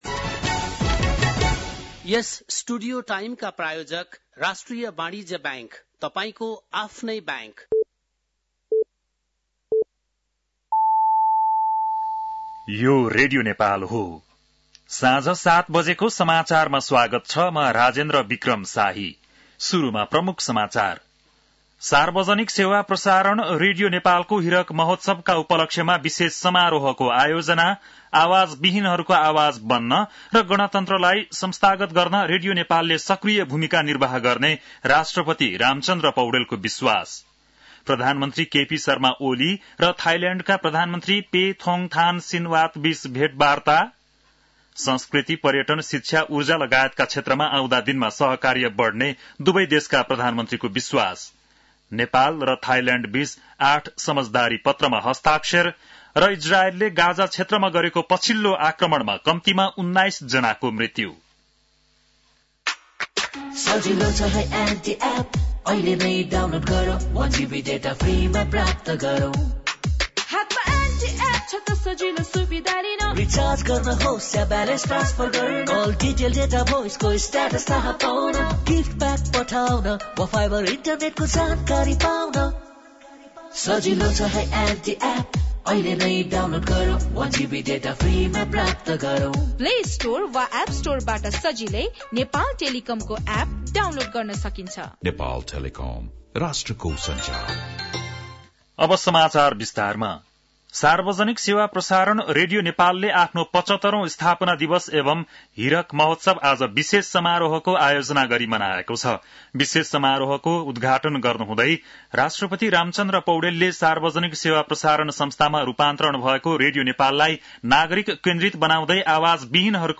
बेलुकी ७ बजेको नेपाली समाचार : २० चैत , २०८१
7-pm-news-1.mp3